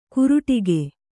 ♪ kuruṭi